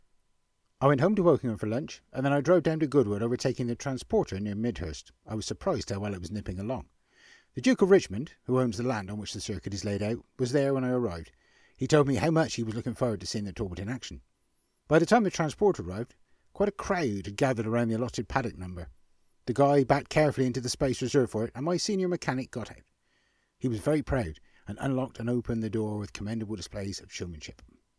Clipped English Narration